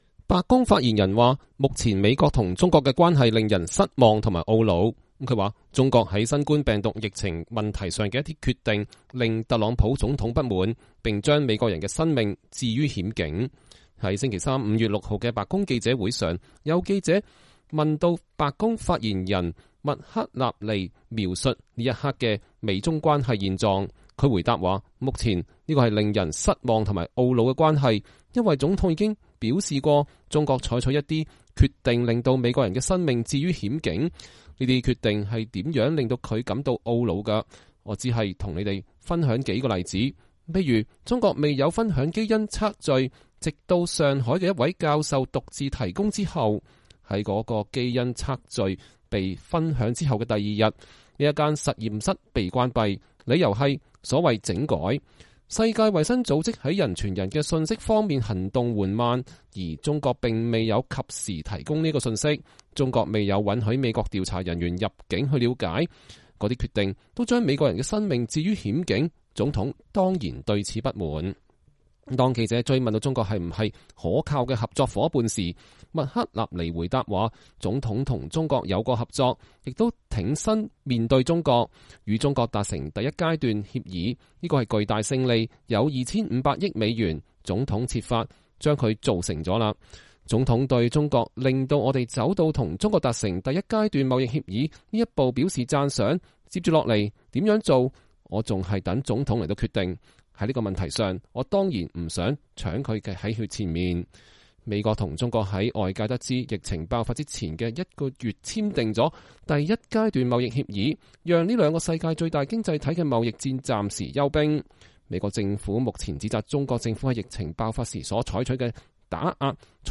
白宮發言人麥克納尼在白宮記者會上講話。(2020年5月6日)
在星期三(5月6日)的白宮記者會上，有記者請白宮發言人凱莉·麥克納尼描述此刻的美中關係現狀。